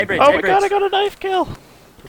knife kill